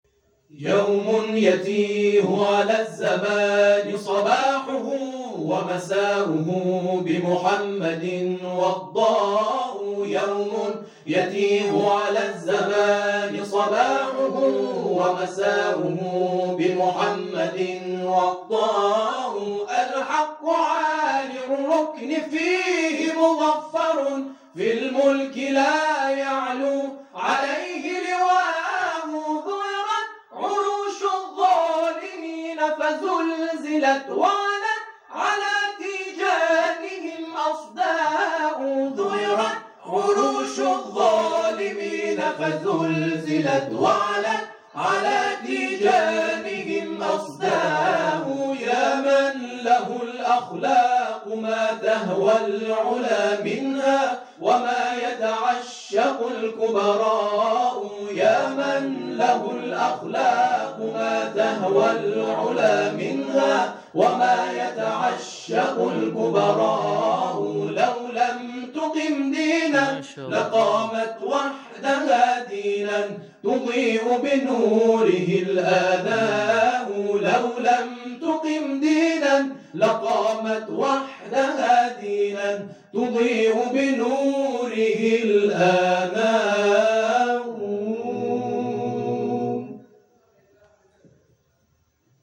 گروه تواشیح اسراء مازندران
در ذیل دقایقی از اجرای این گروه را می‌شنوید.